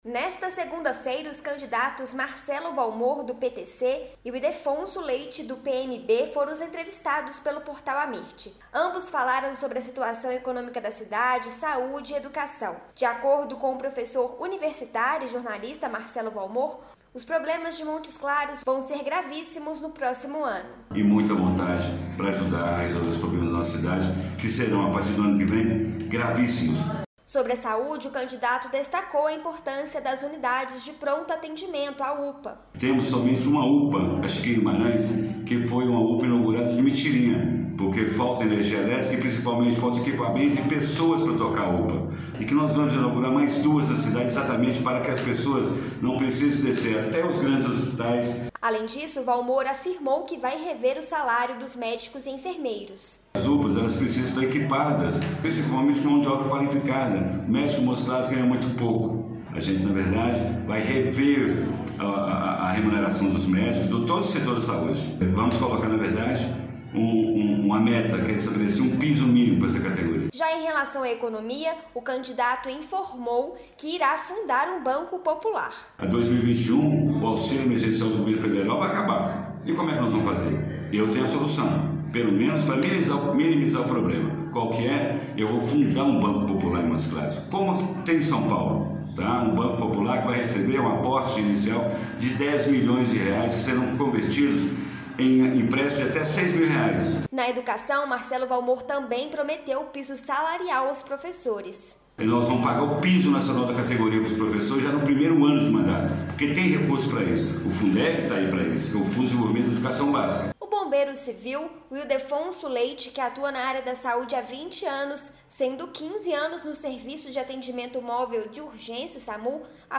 AMIRT Destaque Norte de Minas Notícias em áudio PolíticaThe estimated reading time is 2 minutes
O Portal Amirt realizou entrevistas exclusivas com dezenas de candidatos à prefeitura de cidades mineiras